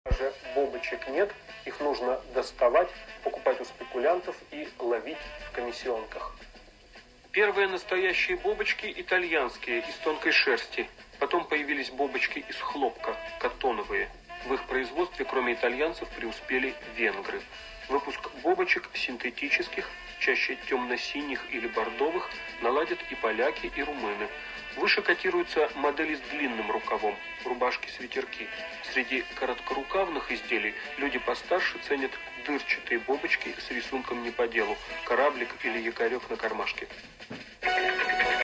Неизвестный твист